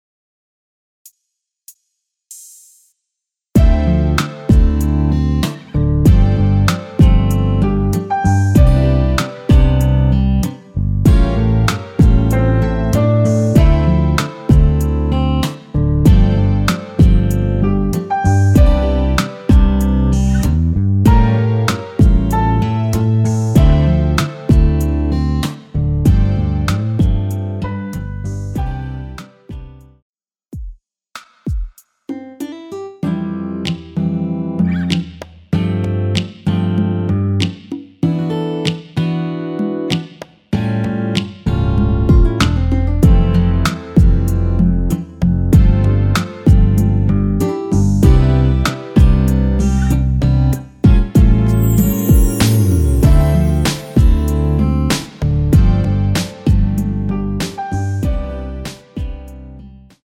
엔딩이 페이드 아웃이라 엔딩을 만들어 놓았습니다.
Dm
◈ 곡명 옆 (-1)은 반음 내림, (+1)은 반음 올림 입니다.
앞부분30초, 뒷부분30초씩 편집해서 올려 드리고 있습니다.